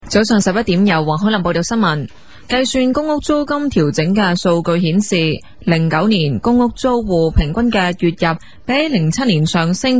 樣本中，香港電台新聞報道員的朗讀速度為45字，即每分鐘大約270字，相對於電視廣播來說電台新聞語速較慢的原因可能是需要給聽眾更多的時間去理解和消化新聞內容，而播音員的朗讀節奏也較為平均，大概為每12-13字一個停頓。